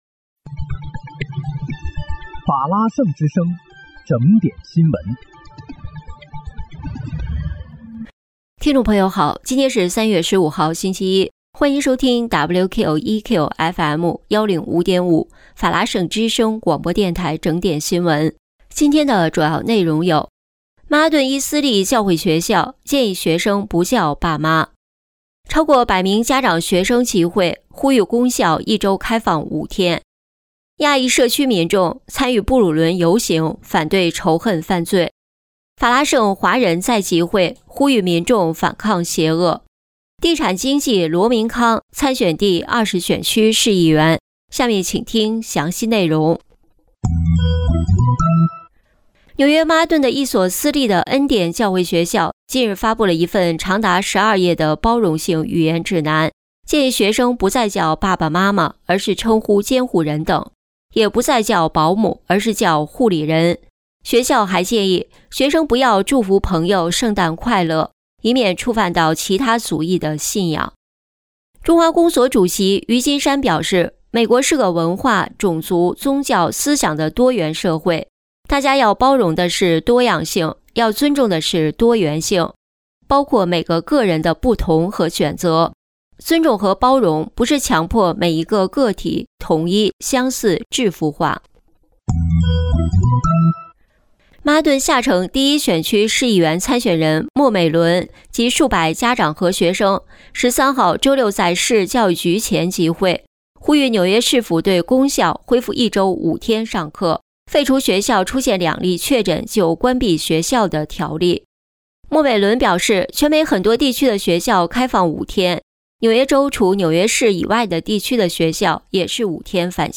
3月15日（星期一）纽约整点新闻
听众朋友您好！今天是3月15号，星期一，欢迎收听WQEQFM105.5法拉盛之声广播电台整点新闻。